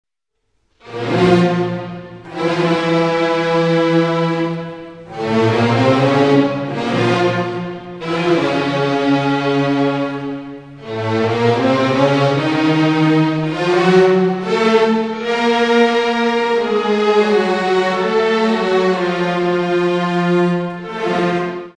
Trascrizione per orchestra di Maurice Ravel